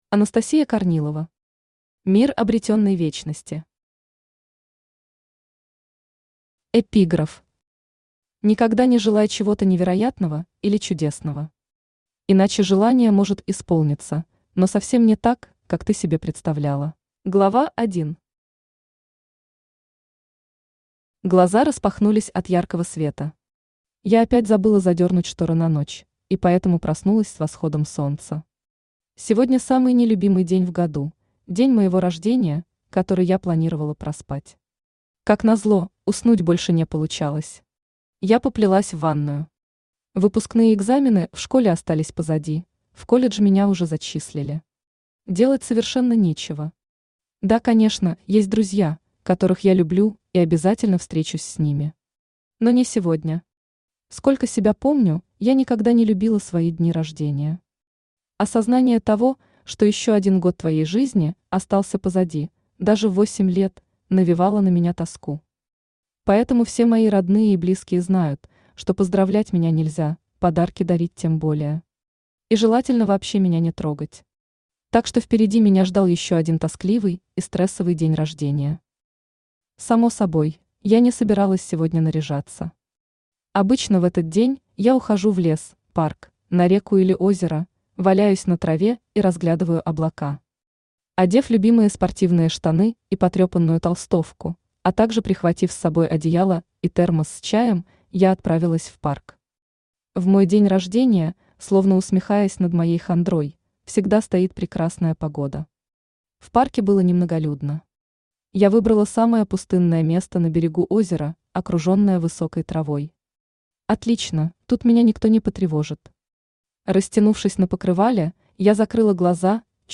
Аудиокнига Мир обретенной вечности | Библиотека аудиокниг
Aудиокнига Мир обретенной вечности Автор Анастасия Корнилова Читает аудиокнигу Авточтец ЛитРес.